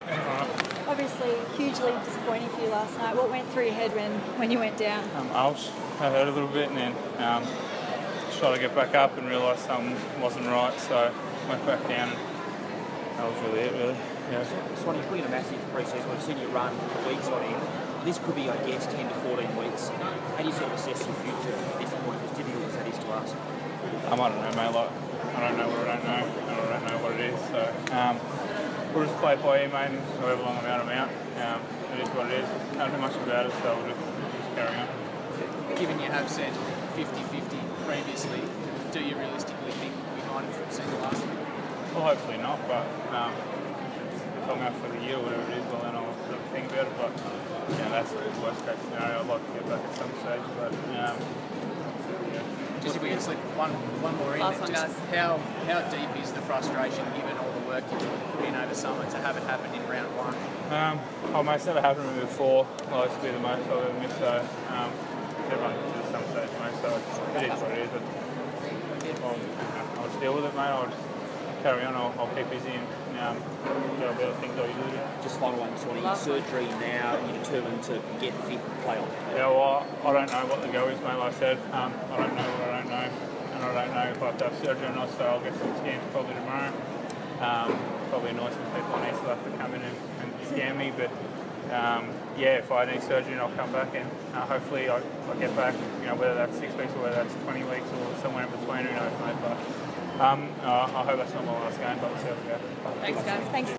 Dane Swan speaks to the media after suffering suspected fractures in his leg during Collingwood's round one loss to Sydney.